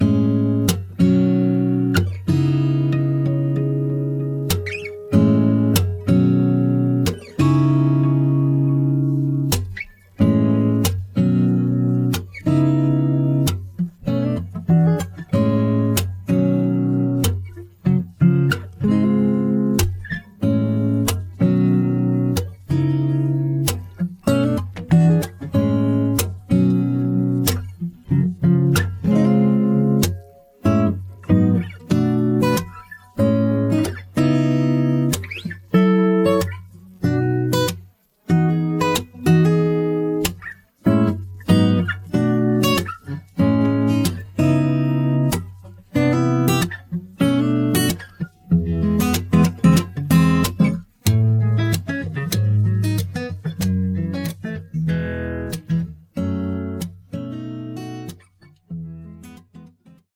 음정 -1키 3:02
장르 가요 구분 Voice Cut